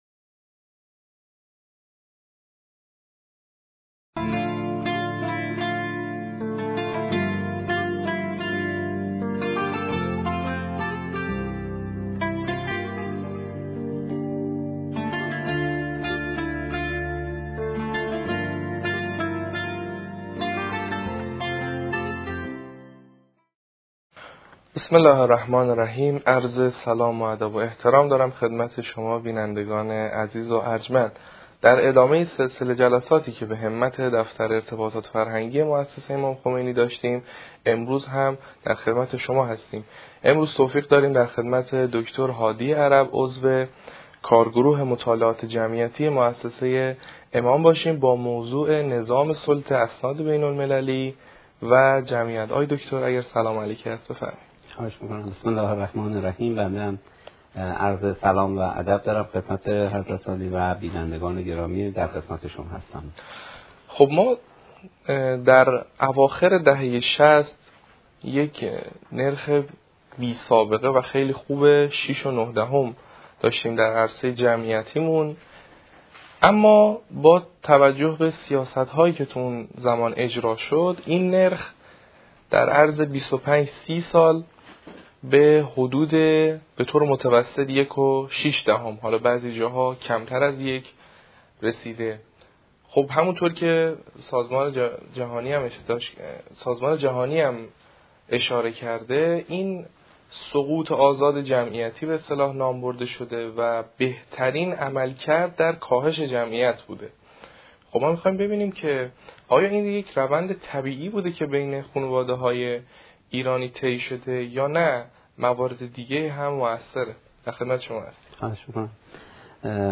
اسناد بین‌المللی و خانواده این گفتگو به بررسی تأثیر نظام سلطه اسناد بین‌المللی بر سیاست‌های جمعیتی ایران می‌پردازد. در دهه ۶۰، نرخ باروری ایران از حدود ۶.۵-۷ فرزند به کمتر از ۱.۱۶ در طول ۲۵ تا ۳۰ سال کاهش یافت که روندی بی‌سابقه و سریع در جهان بود.